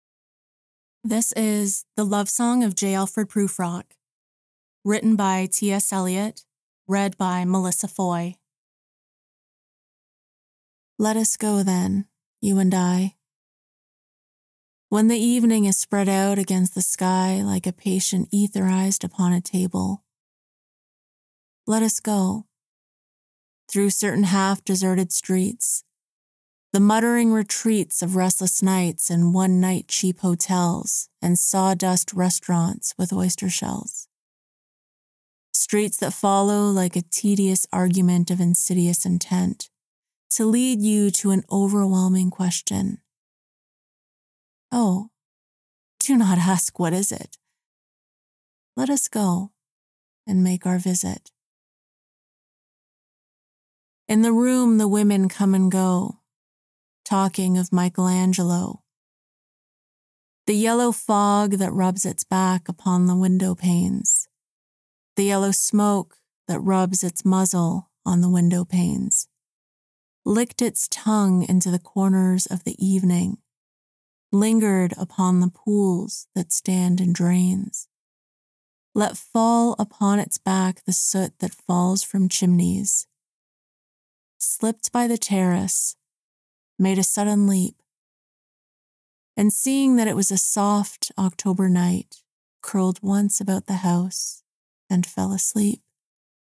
2. Poetry (The Love Song of J. Alfred Prufrock)
I have a professional sound treated recording studio with industry standard microphones, equipment, and recording / audio editing software.